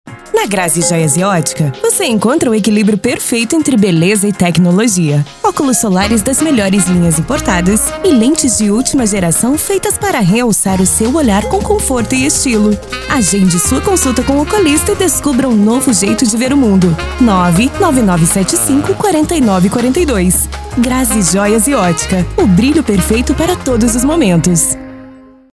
Spot Comercial
Impacto
Animada